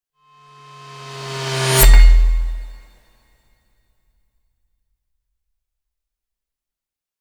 Whoosh Transition Sound Effect Free Download
Whoosh Transition